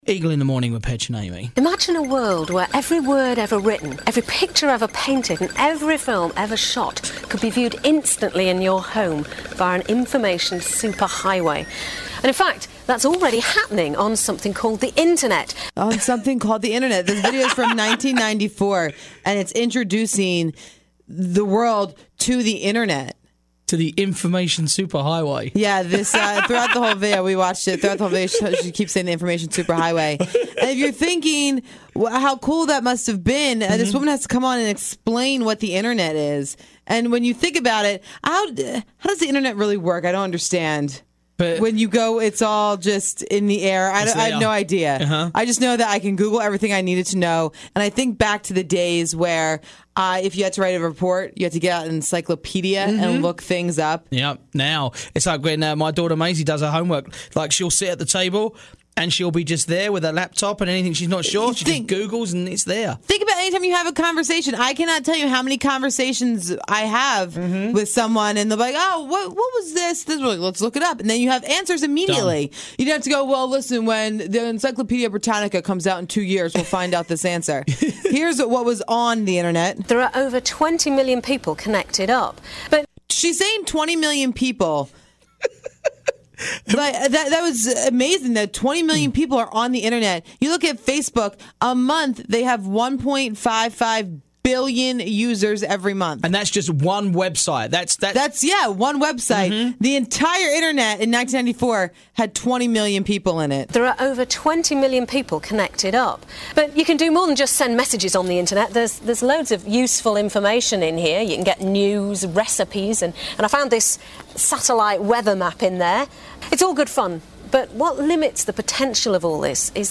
We found some audio of a female reporter talking about the internet from 1994, Funny how far we have come.